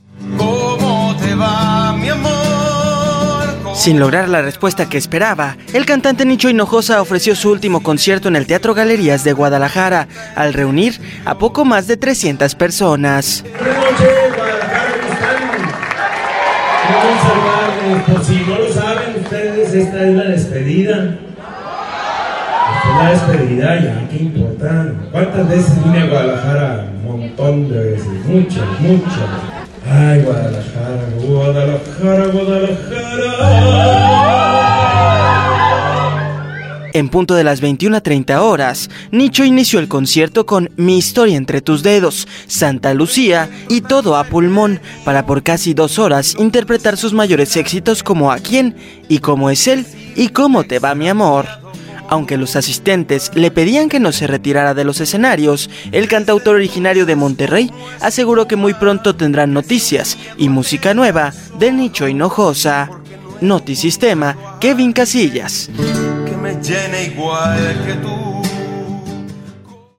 Sin lograr la respuesta que esperaba, el cantante Nicho Hinojosa ofreció su último concierto en el Teatro Galerías de Guadalajara al reunir a poco más de 300 personas.